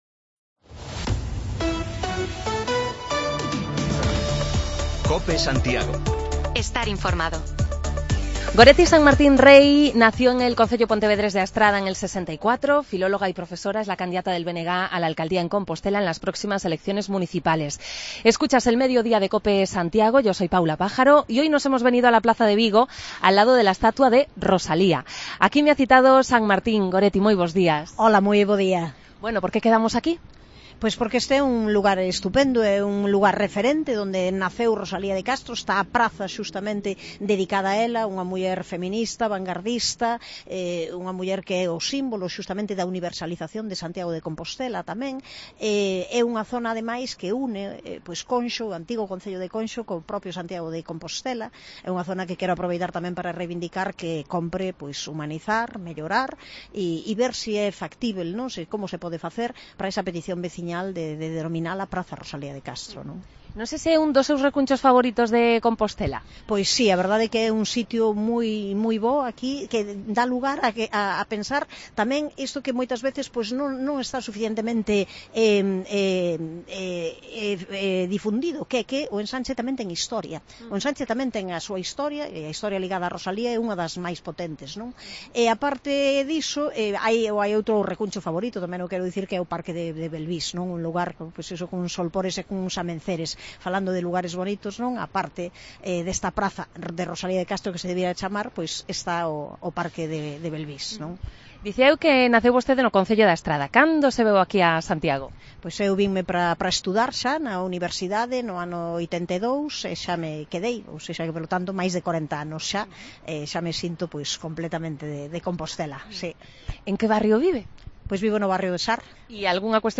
Con Goretti Sanmartín iniciamos una ronda de entrevistas para conocer de cerca a los principales candidatos a la alcaldía de Santiago el próximo 28 de Mayo. La líder nacionalista en Compostela nos cuenta algunos de sus proyectos para la capital de Galicia, pero también qué prefiere para desayunar por las mañanas o, como profe que es, qué nota le pone al actual regidor municipal tras esta legislatura que toca a su fin.